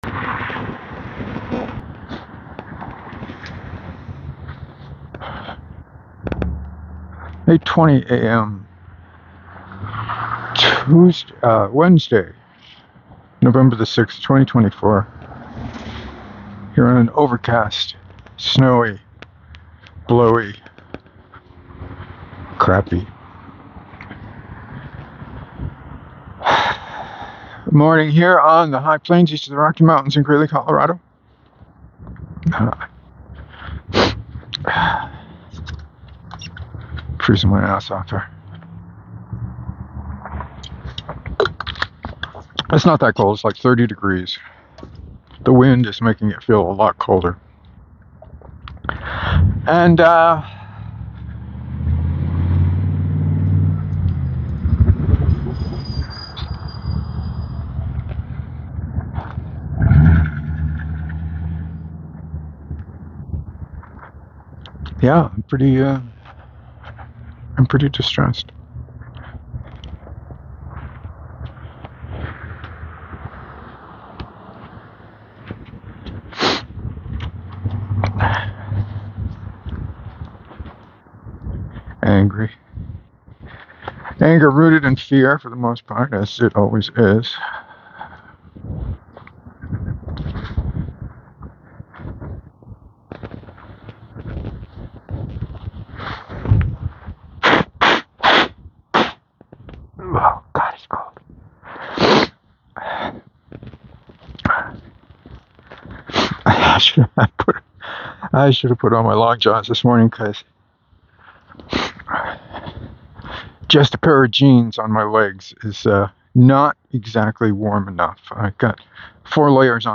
There’s a lot of boot sounds mixed in with the fear.
I think that’s what that background buzzing is.